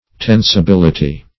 Search Result for " tensibility" : The Collaborative International Dictionary of English v.0.48: Tensibility \Ten`si*bil"i*ty\, n. The quality or state of being tensible; tensility.